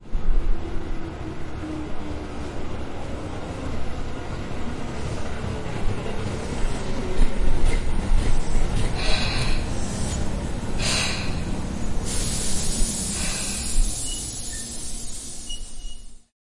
萨格勒布 " 萨格勒布迪塞尔缓缓走过
描述：缩放H1萨格勒布火车站早上通勤列车
标签： deisel 火车 铁路 轨道
声道立体声